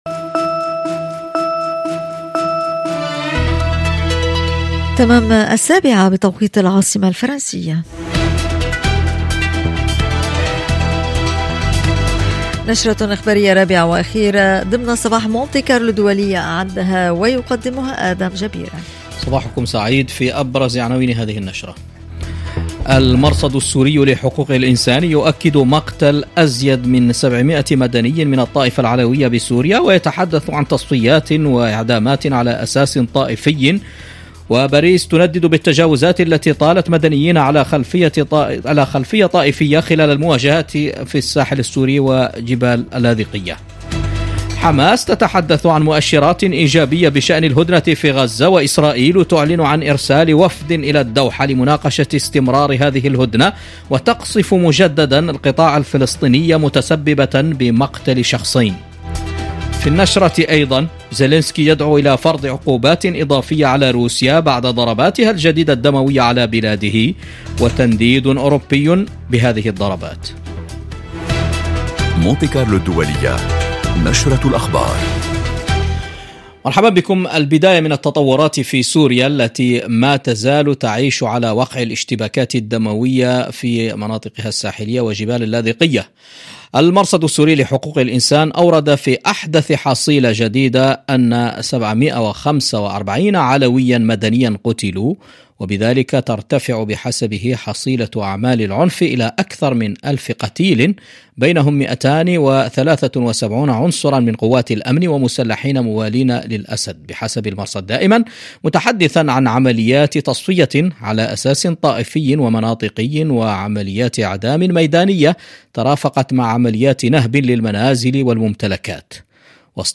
برامج مونت كارلو الدولية من تقديم صحفيين ومذيعين متخصصين وتعتمد في أغلبها على التواصل اليومي مع المستمع من خلال ملفات صحية واجتماعية ذات صلة بالحياة اليومية تهم المرأة والشباب والعائلة، كما أنها تشكل نقطة التقاء الشرق بالغرب والعالم العربي بفرنسا بفضل برامج ثقافية وموسيقية غنية.